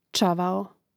čȁvao čavao im. m. (G čȃvla, DL čȃvlu, A čȃvao, I čȃvlom; mn.